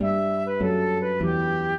flute-harp
minuet9-11.wav